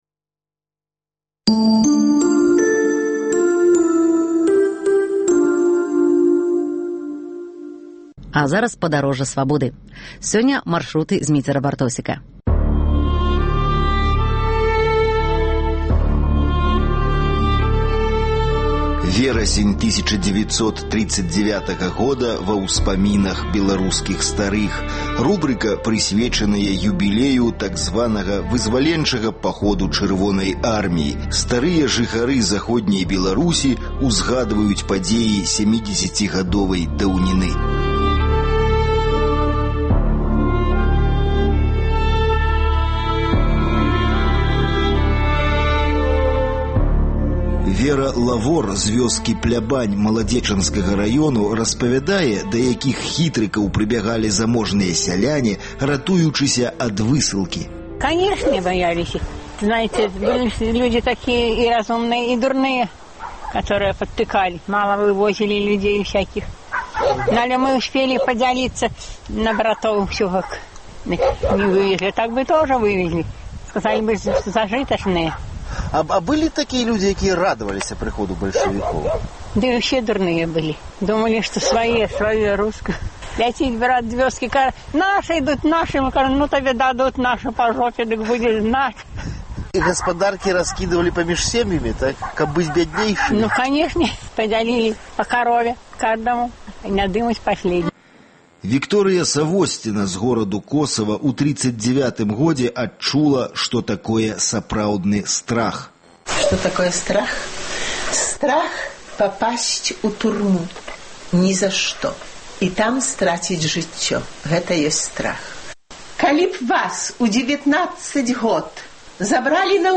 Штодня да 17 верасьня ў эфіры і на сайце "Свабоды" мы прапануем вашай увазе успаміны людзей пра верасень 1939 году, калі пачалася Другая ўсясьветная вайна і калі 17 верасьня савецкія войскі ўвайшлі ў Заходнюю Беларусь.